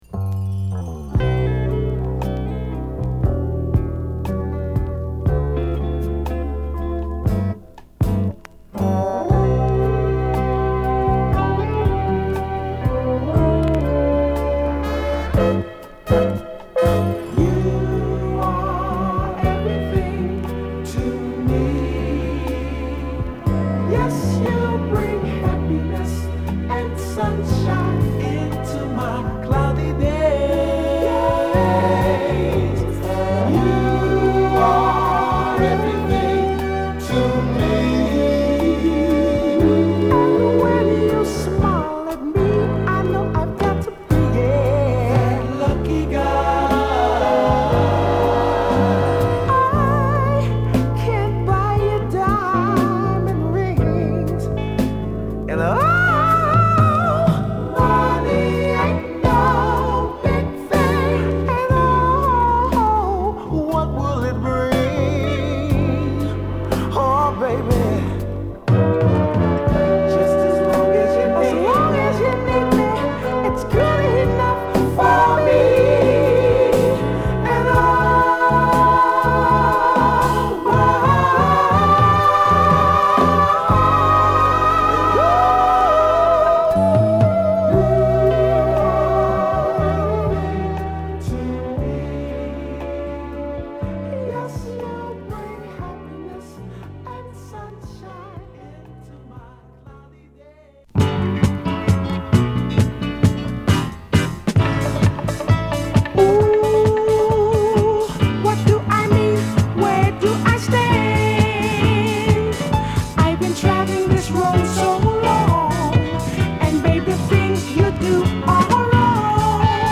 ファルセットが冴える軽やかなスウィート